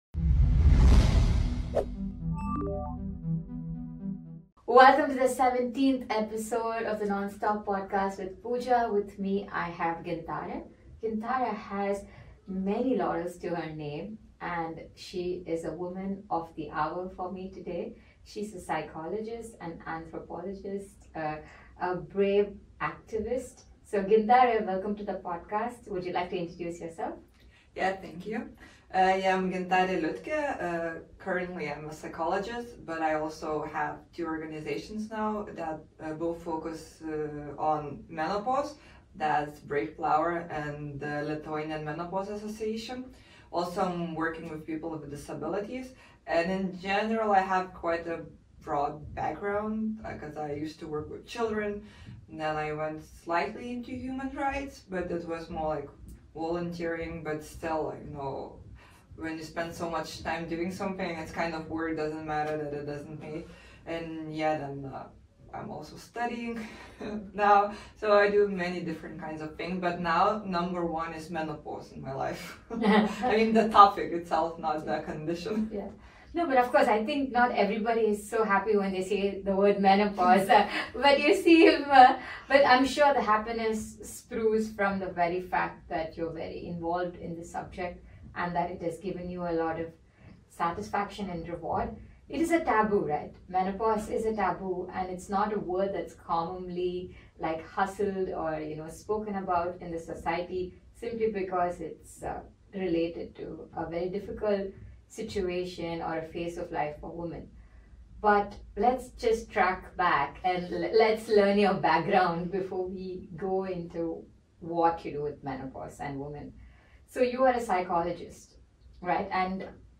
Join us for a deep, honest, and empowering conversation on mental health, activism, and the power of listening to your body.